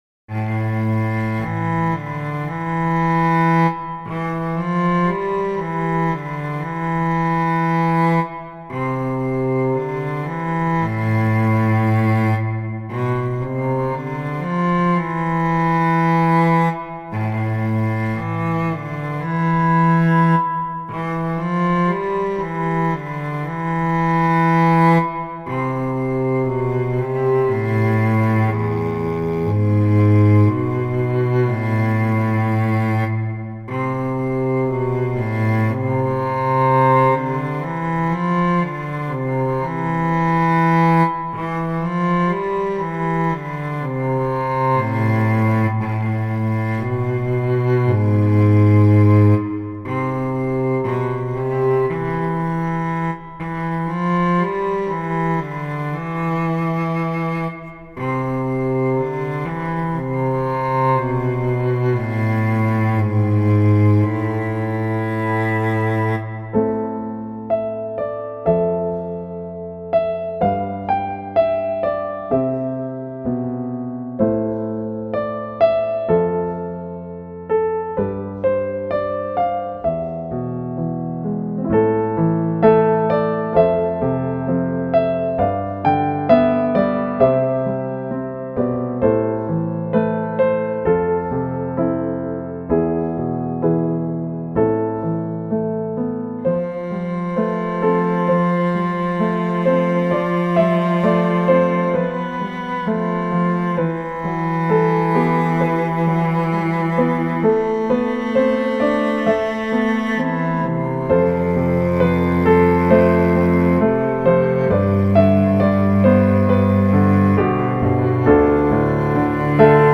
テンポ：♩=free
主な使用楽器：ピアノ、チェロ